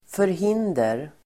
Uttal: [förh'in:der]